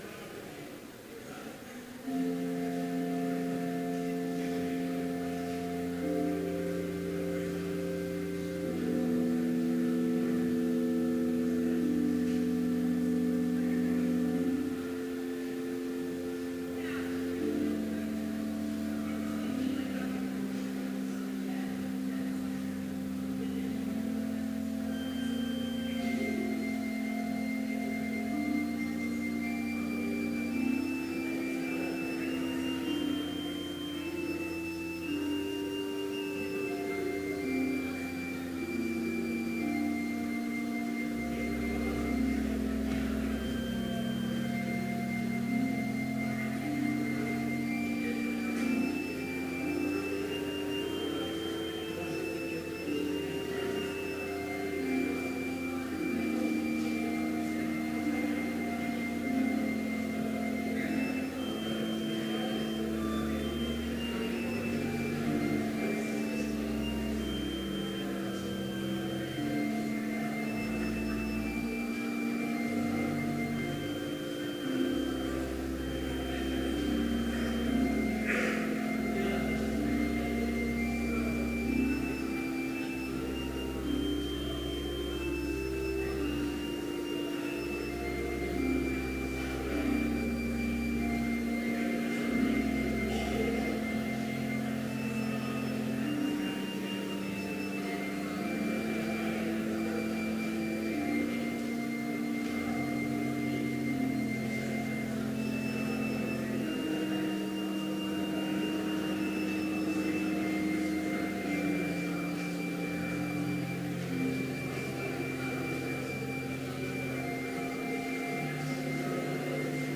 Complete service audio for Chapel - February 28, 2017